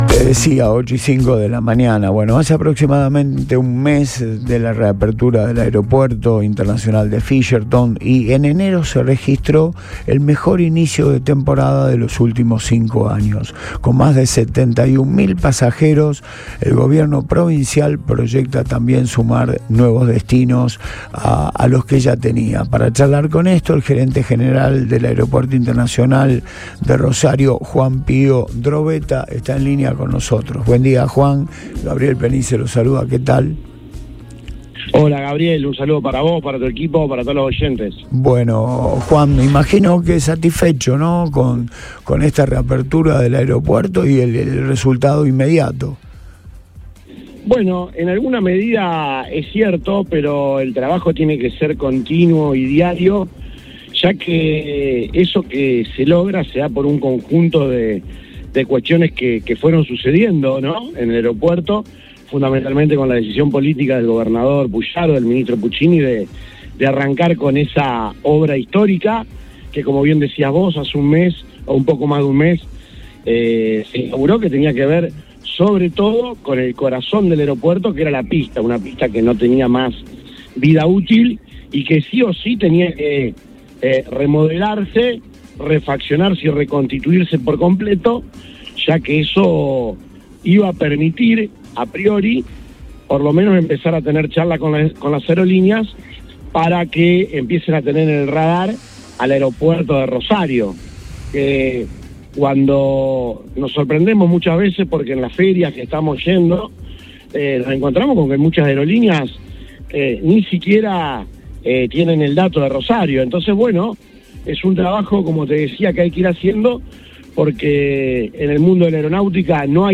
En diálogo con el programa Antes de Todo de Radio Boing